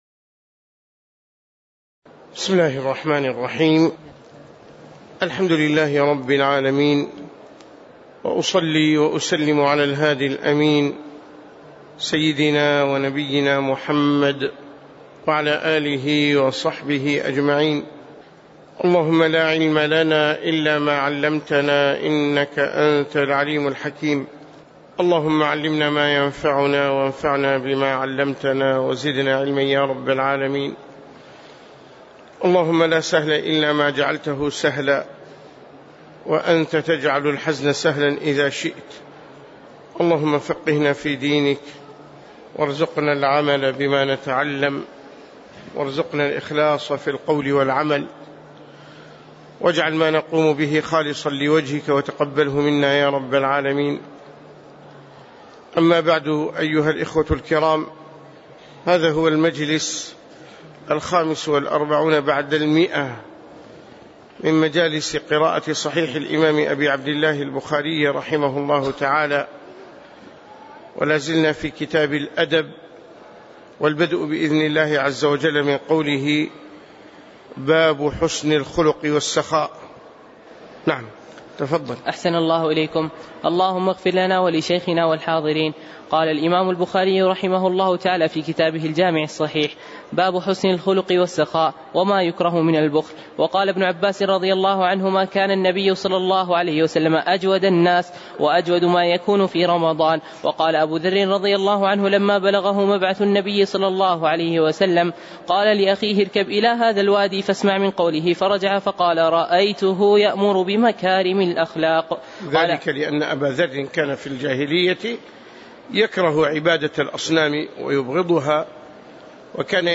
تاريخ النشر ١٣ محرم ١٤٣٩ هـ المكان: المسجد النبوي الشيخ